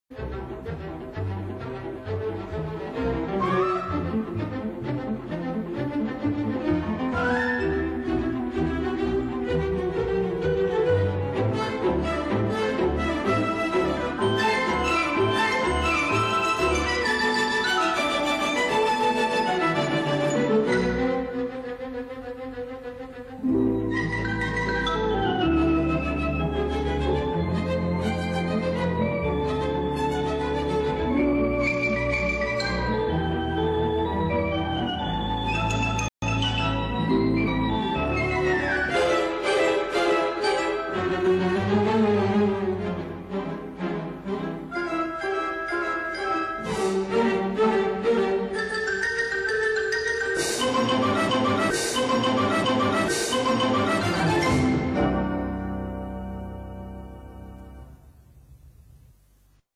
Suite in 4 Sätzen für Kleines Orchester